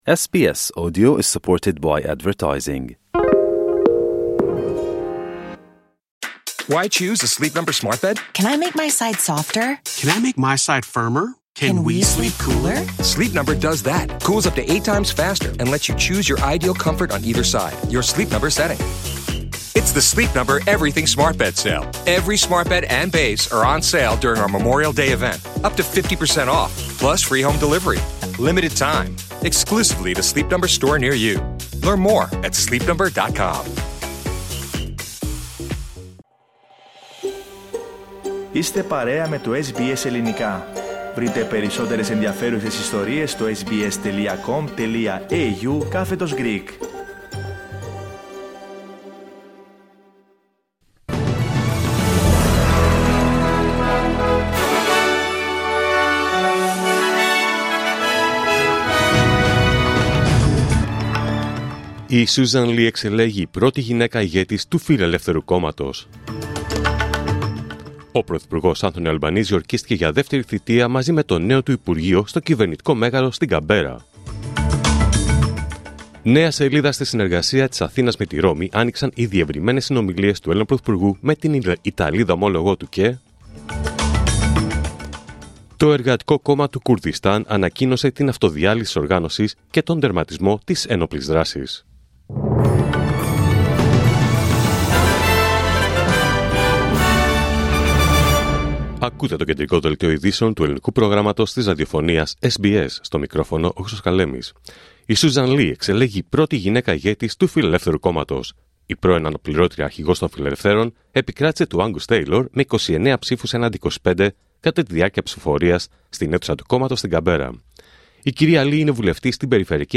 Δελτίο Ειδήσεων Τρίτη 13 Μαΐου 2025